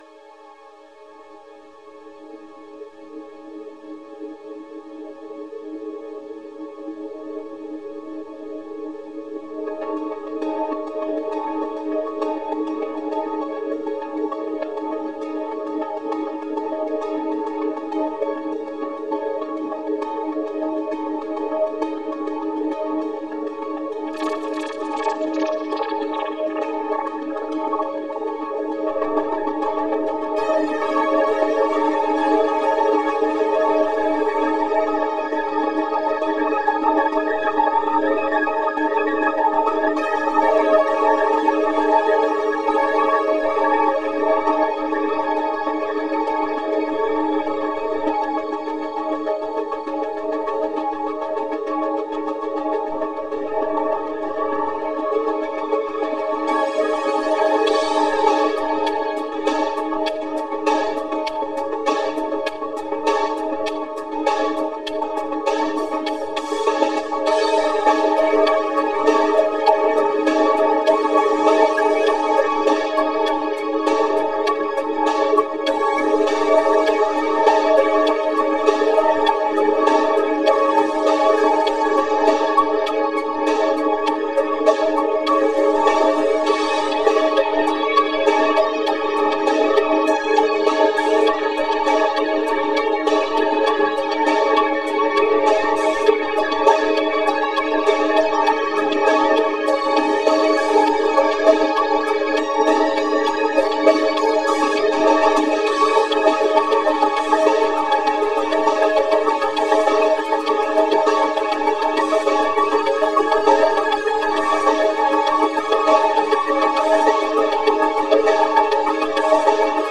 jazz vocoder.mp3